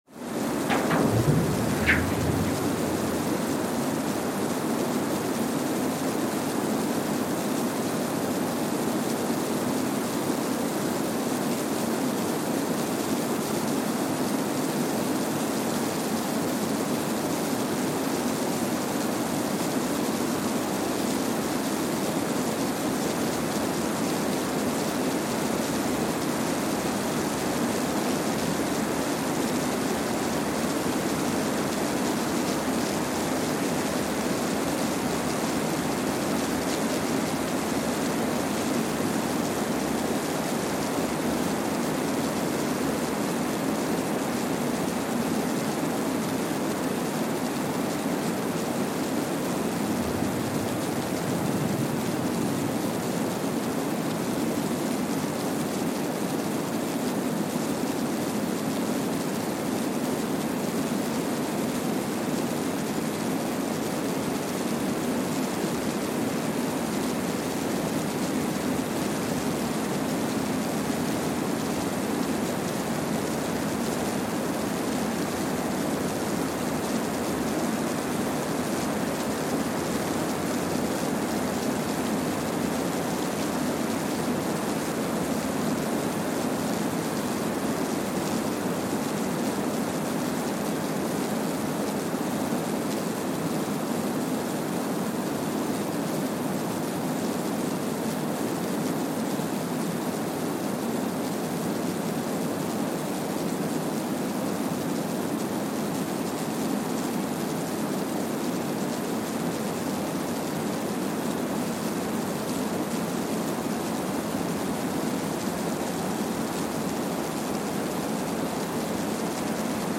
Kwajalein Atoll, Marshall Islands (seismic) archived on July 12, 2023
Sensor : Streckeisen STS-5A Seismometer
Speedup : ×1,000 (transposed up about 10 octaves)
Loop duration (audio) : 05:45 (stereo)
Gain correction : 25dB
SoX post-processing : highpass -2 90 highpass -2 90